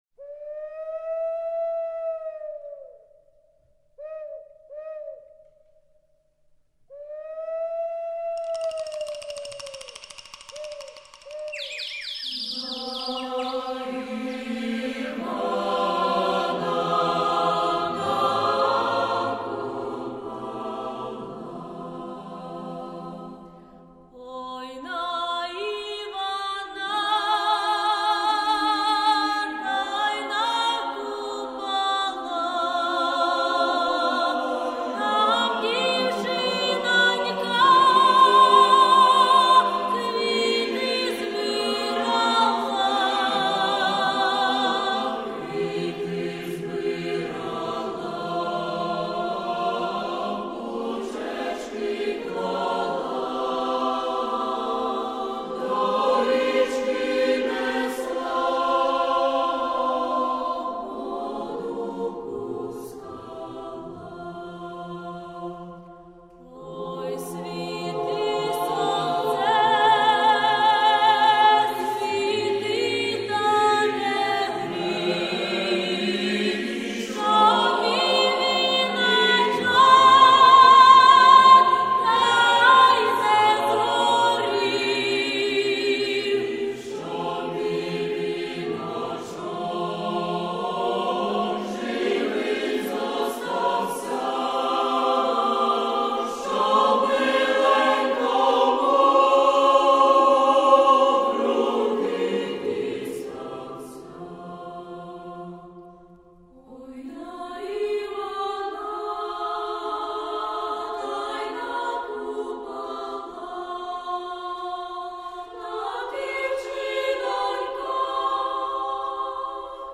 Гарна народня мелодія і ваші слова